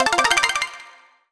rolling01.wav